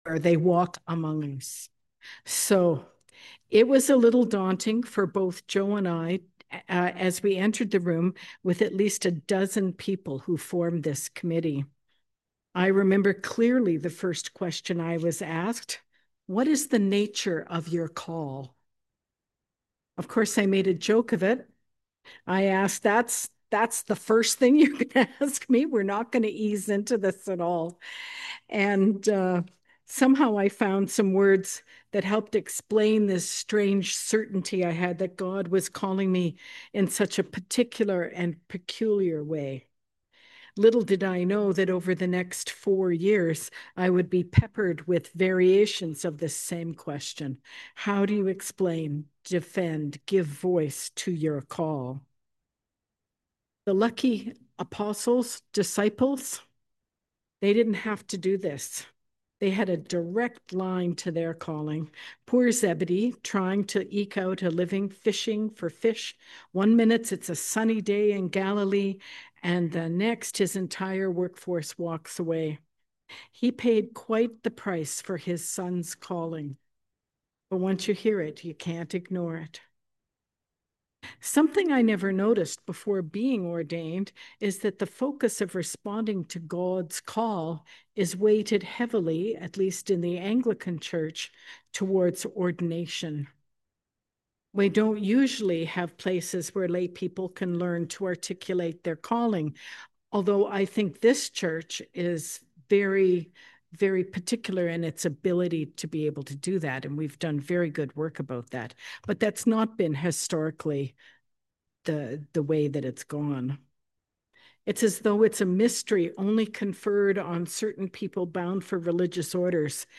Sermon on the Third Sunday after Epiphany